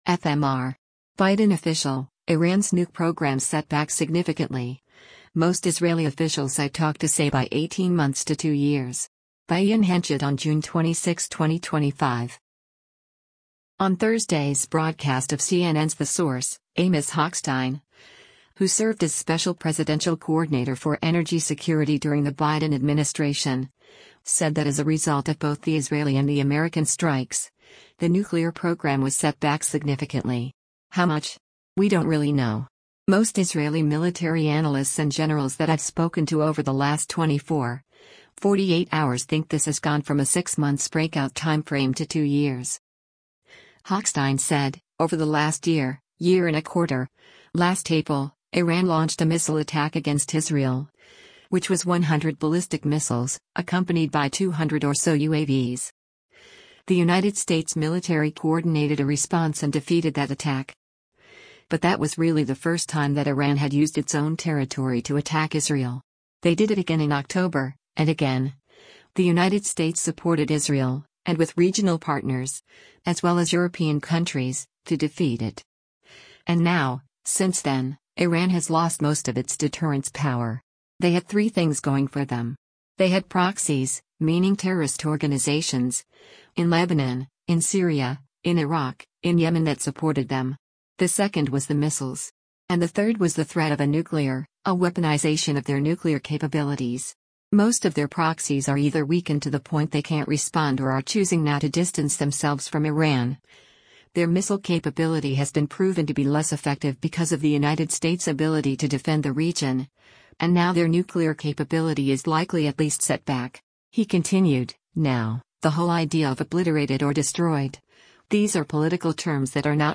On Thursday’s broadcast of CNN’s “The Source,” Amos Hochstein, who served as Special Presidential Coordinator for Energy Security during the Biden administration, said that “as a result of both the Israeli and the American strikes, the nuclear program was set back significantly. How much? We don’t really know. Most Israeli military analysts and generals that I’ve spoken to over the last 24, 48 hours think this has gone from a six-months breakout timeframe to two years.”